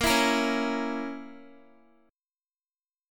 Bbdim chord